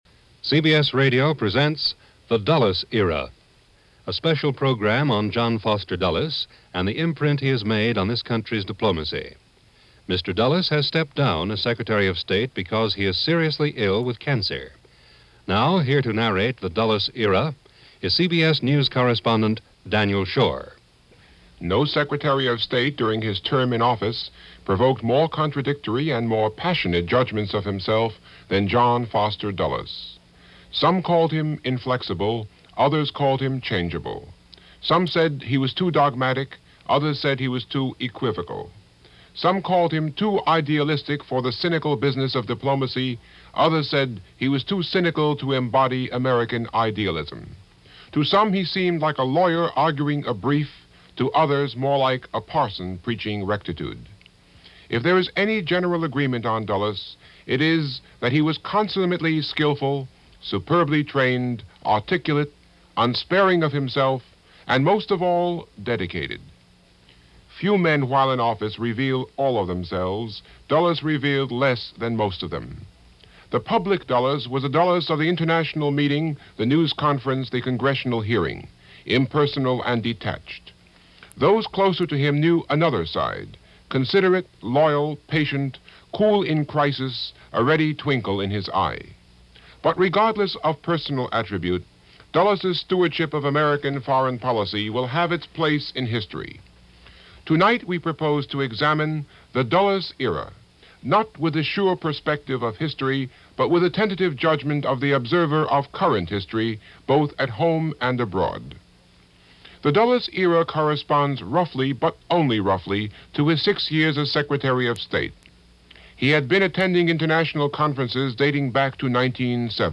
Here is that complete broadcast from April 15, 1959.